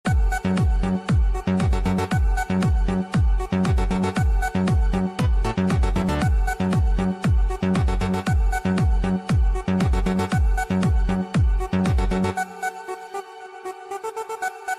AJF Attitude Sound Effect
A distinct vocal sound effect conveying a strong, confident attitude.
ajf-attitude-sound-effect-9cd19b62.mp3